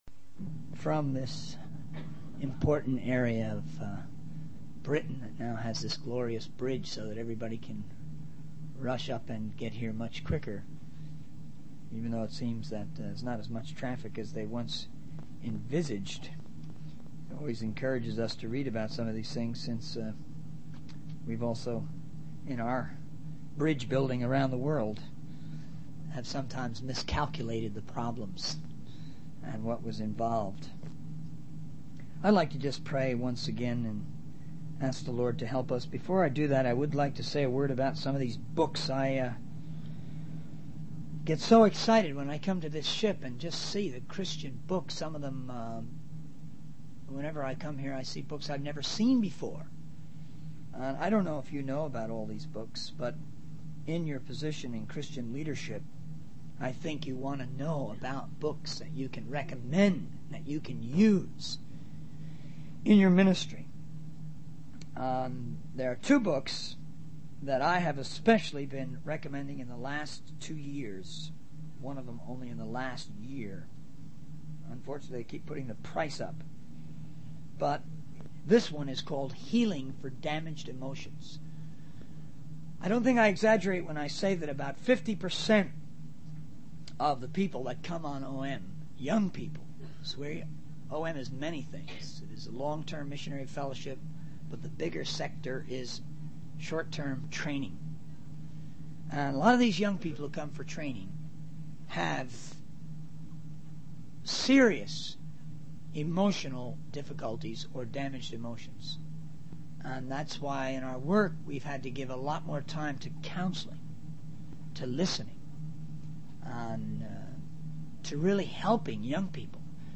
In this sermon, the speaker emphasizes the importance of not being moved or discouraged by the challenges and burdens that come with ministry. He encourages listeners to renew their commitment to biblical ministry and to study and preach on Acts 20, which discusses feeding the flock of God.